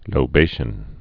(lō-bāshən)